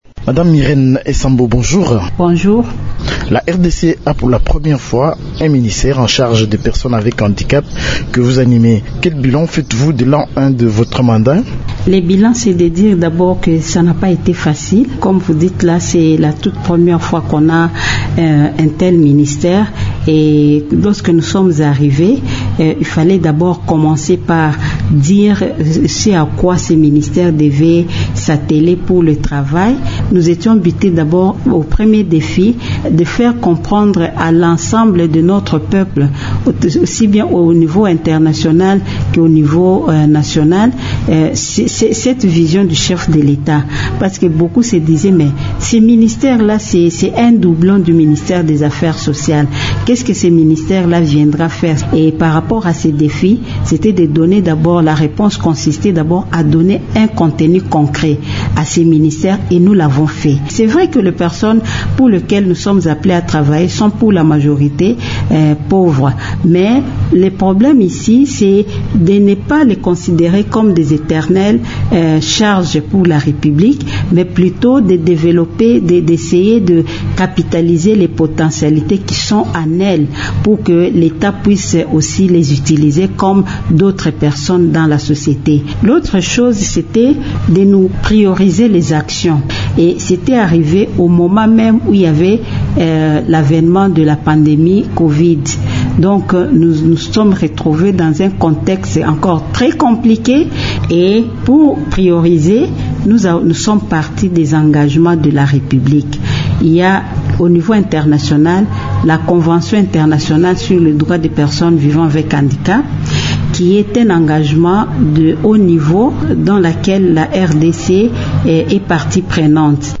irene_esambo.mp3